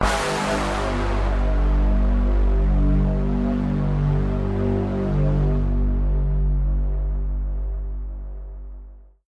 Звуки проигрыша
На этой странице собрана коллекция звуков проигрыша и «Game Over» из различных игр.
Заставка гейм овер